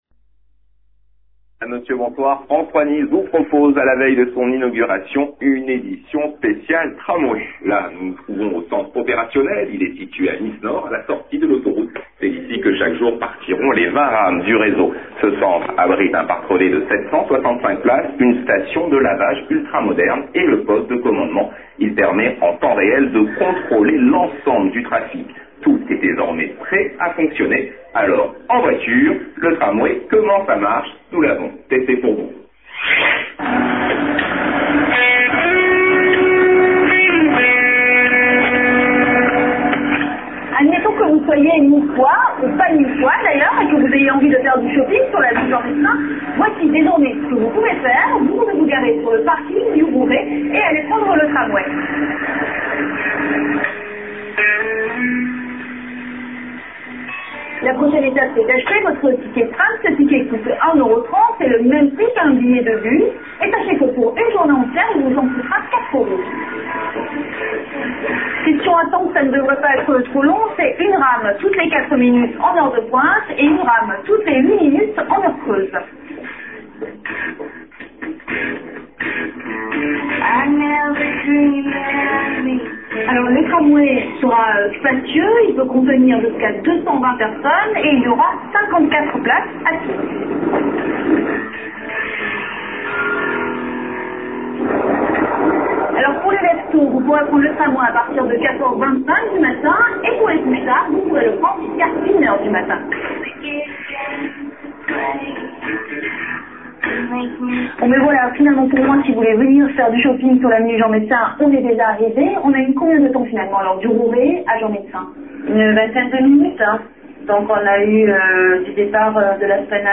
JT FRANCE 3 SPECIAL TRAMWAY Demain, ce sera une réalité.